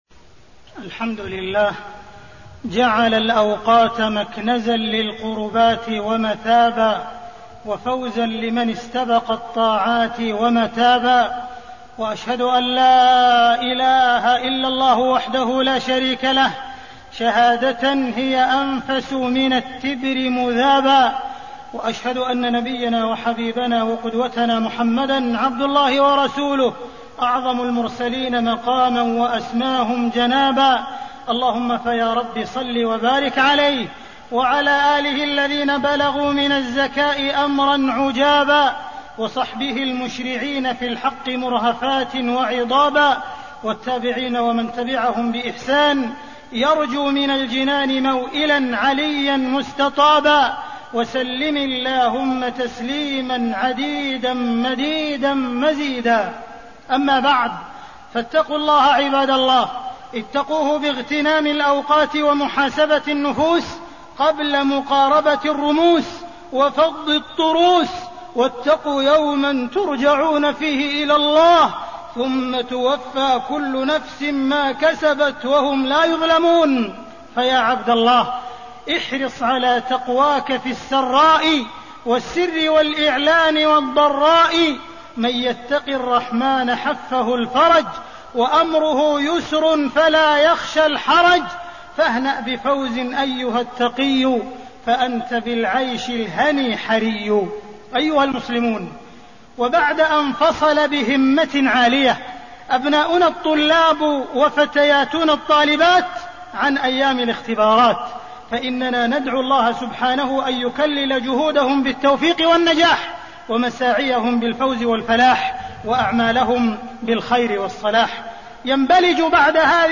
تاريخ النشر ١٠ رجب ١٤٣٠ هـ المكان: المسجد الحرام الشيخ: معالي الشيخ أ.د. عبدالرحمن بن عبدالعزيز السديس معالي الشيخ أ.د. عبدالرحمن بن عبدالعزيز السديس الاجازة وكيفية استغلالها